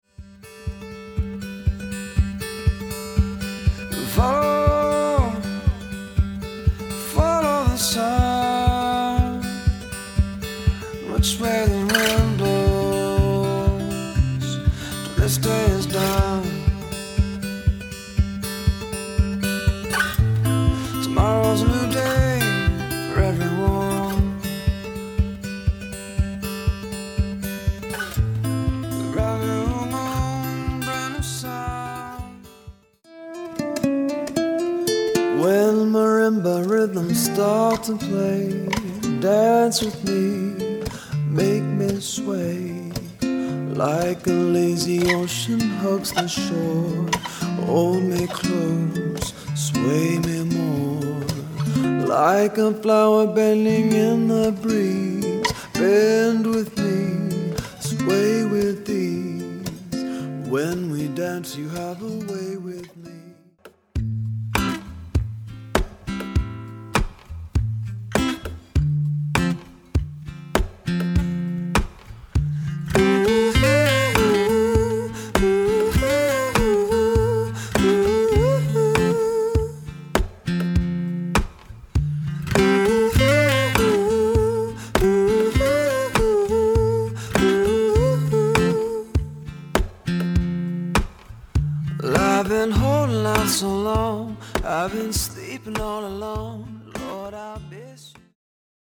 an eclectic mix of folk, funk, rock and techno